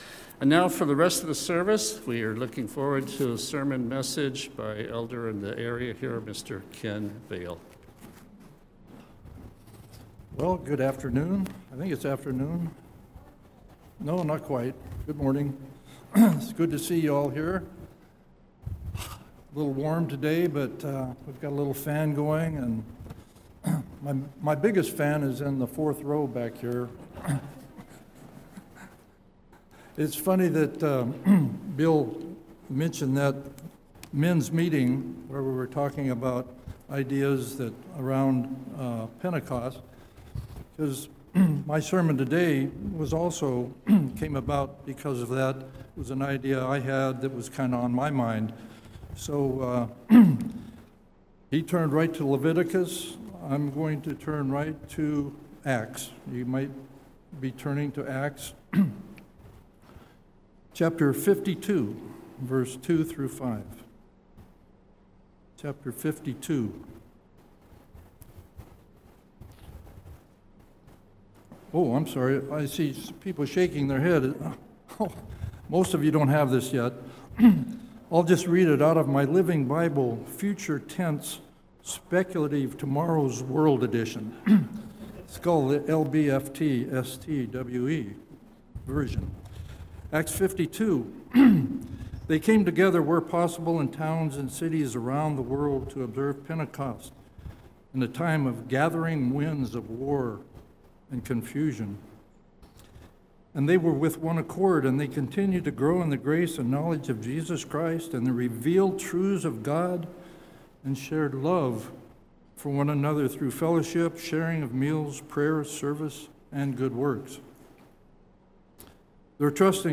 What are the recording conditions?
Given in Olympia, WA Tacoma, WA